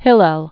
(hĭlĕl, -āl, hē-lĕl) fl. first century BC-first century AD.